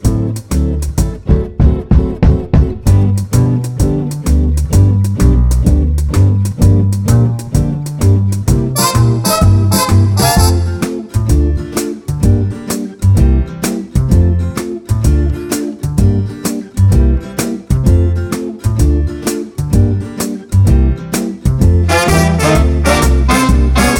No Vocals At All Pop (1950s) 2:14 Buy £1.50